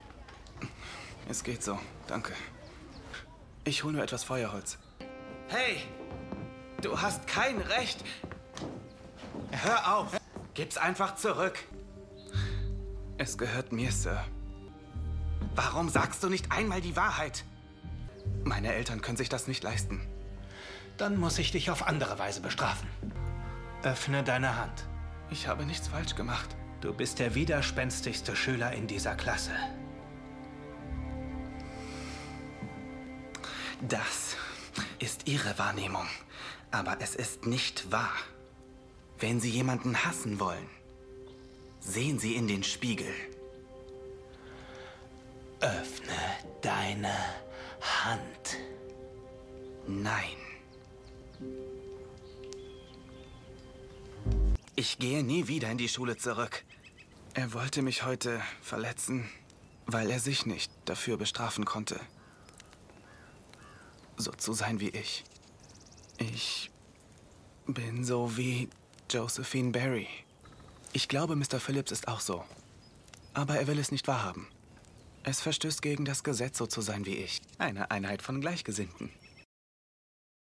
TV Shows
Soundproof recording booth – 38 dB in the midrange and up to 84 dB in the high frequency range